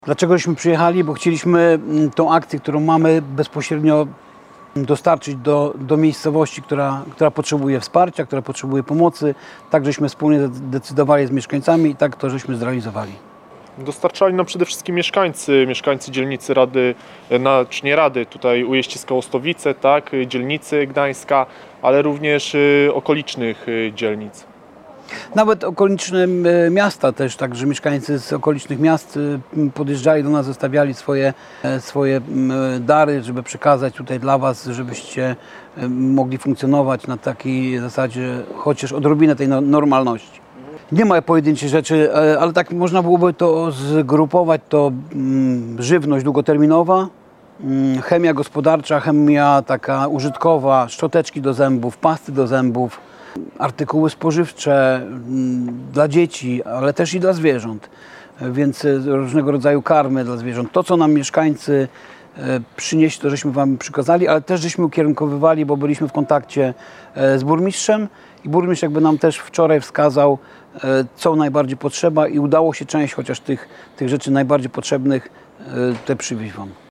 Dlaczego przyjechaliśmy? Bo chcieliśmy bezpośrednio dostarczyć pomoc do gminy, która potrzebuje wsparcia. Tak zdecydowaliśmy wspólnie z mieszkańcami, i tak to zrealizowaliśmy – mówili dzisiaj o poranku dwaj mieszkańcy Gdańska, którzy dotarli do gminy Czechowice-Dziedzice z pomocą.